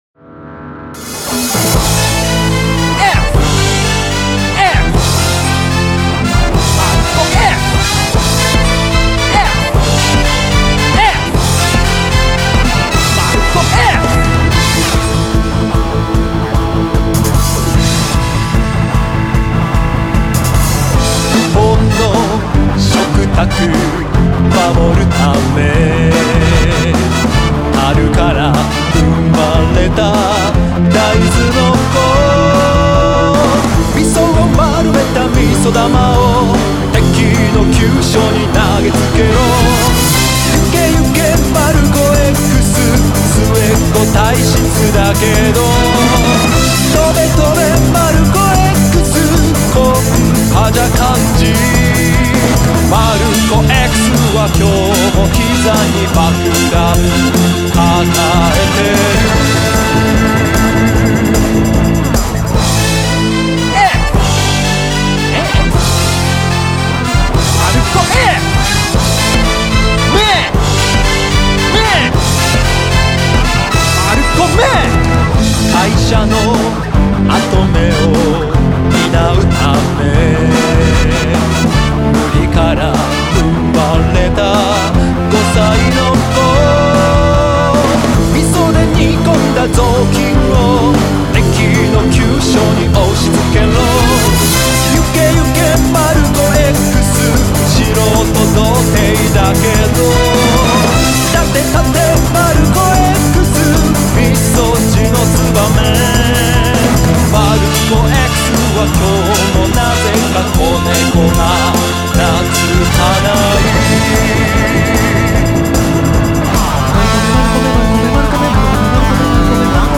勝手にCMソング
純和風の調味料を、まさかのアメコミ風特撮ヒーロー演出で宣伝するまったくの非公式企画です。